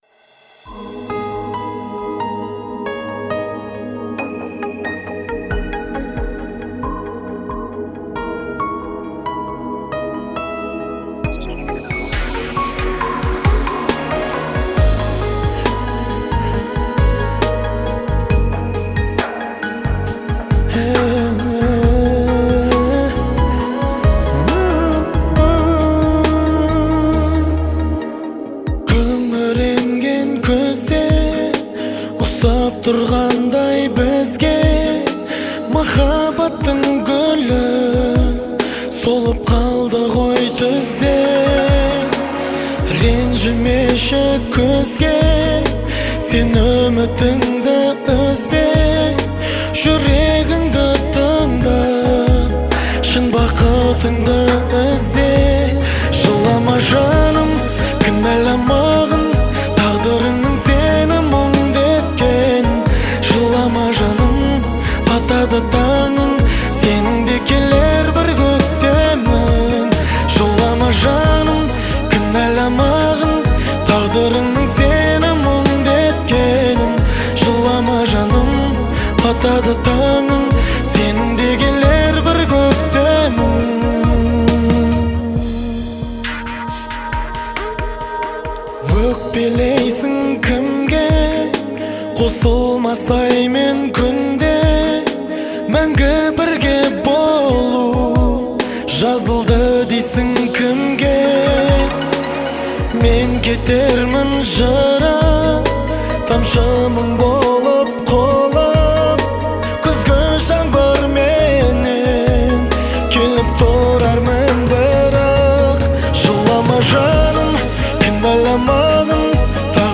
это казахская песня в жанре поп